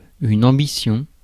Ääntäminen
IPA : /æmˈbɪ.ʃən/